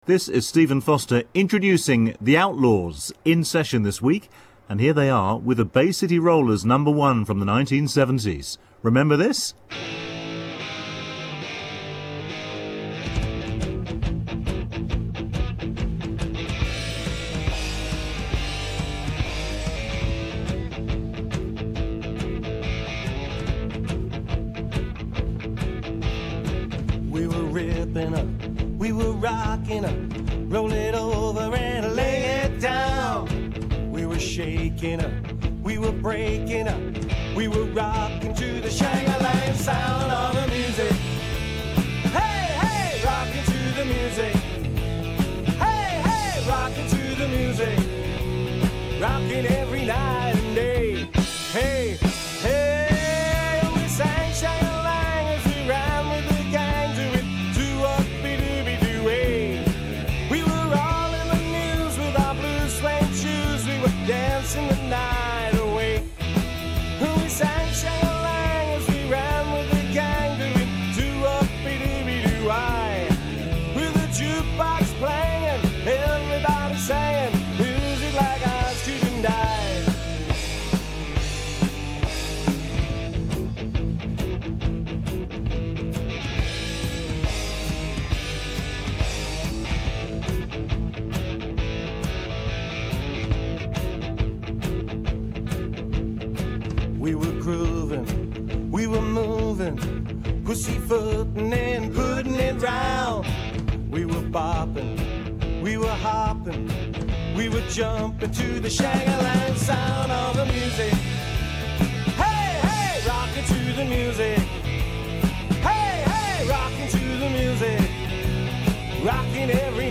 sounded fine♫